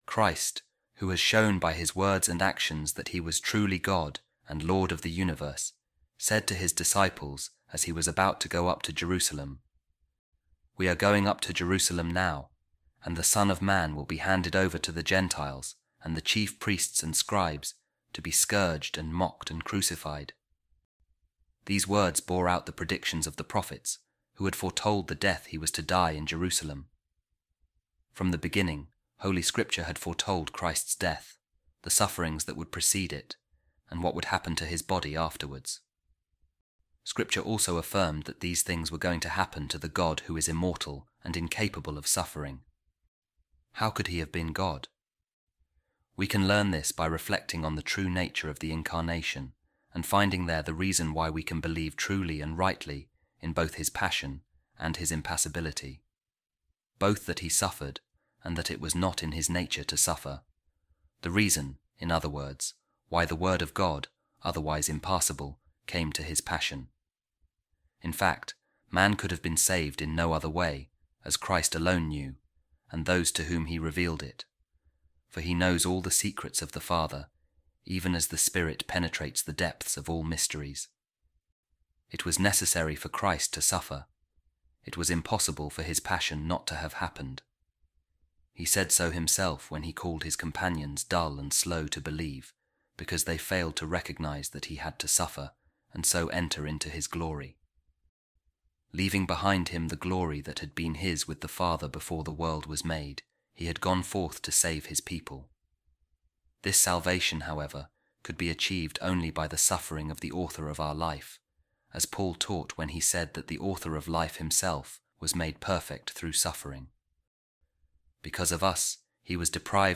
A Reading From The Addresses Of Saint Anastasius Of Antioch